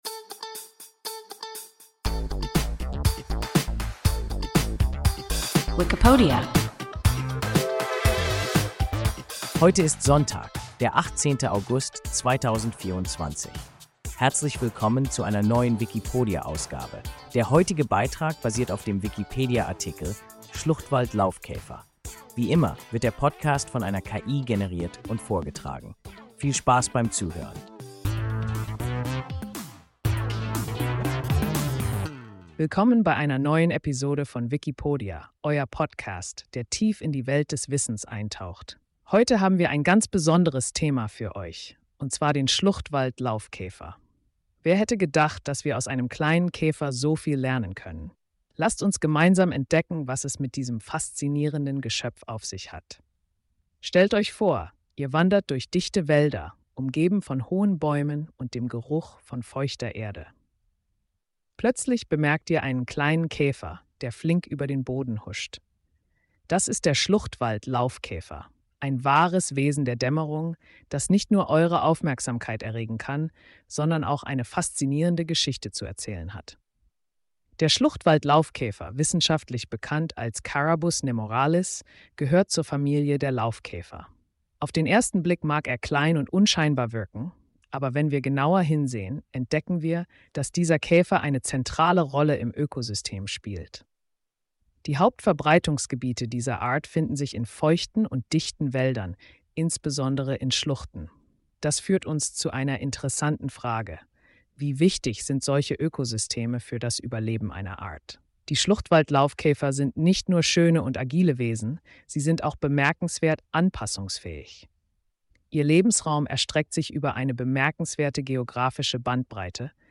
Schluchtwald-Laufkäfer – WIKIPODIA – ein KI Podcast